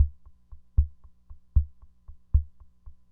Index of /90_sSampleCDs/300 Drum Machines/FG Enterprises King Beat/FG Enterprises King Beat Sample Pack_Audio Files
FG Enterprises King Beat Sample Pack_Loop6.wav